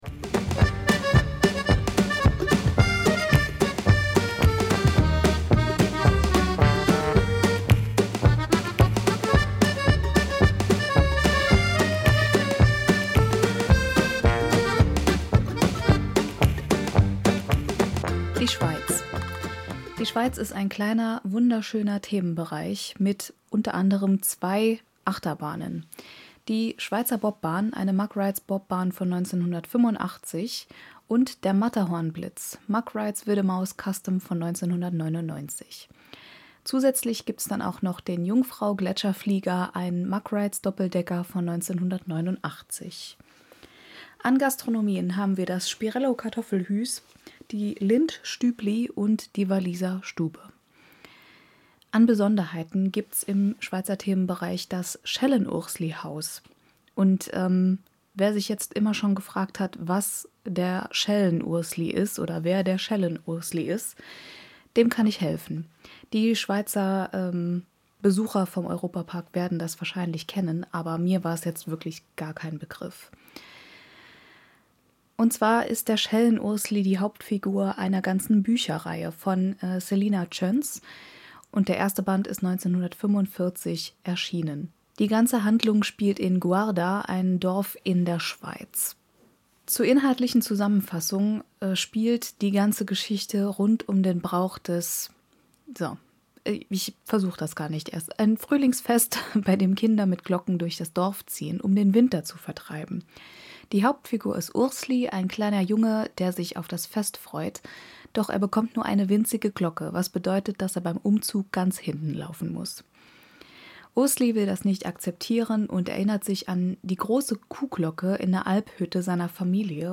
Music by Tunetank from Pixabay